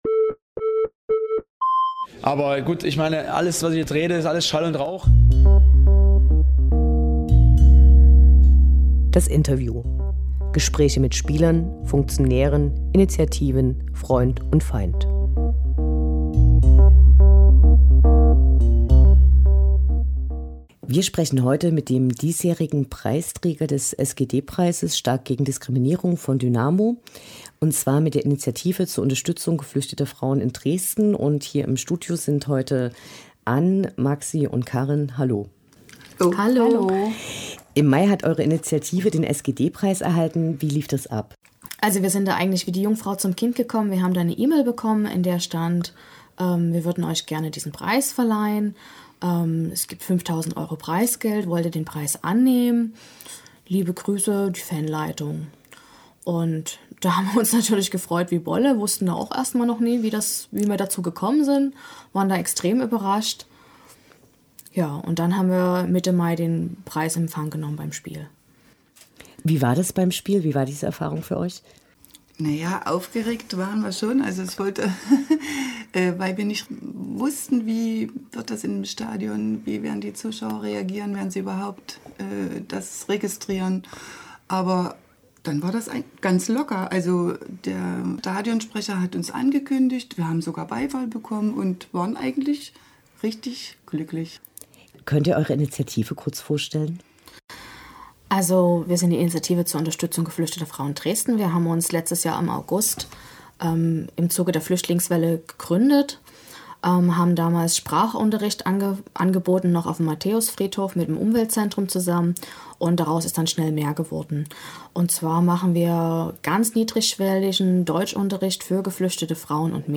Interview mit den SGD-Preisträgern 2016
Interview mit den Preisträgern des SGD- Preises (Stark gegen Diskriminierung) 2016, der Initiative zur Unterstützung geflüchteter Frauen in Dresden.